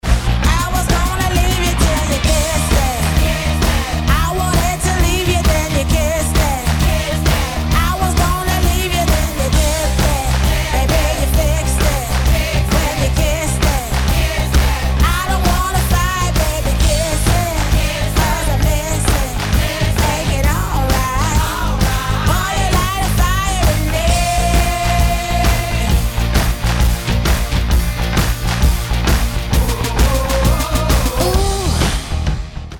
rockovou kapelou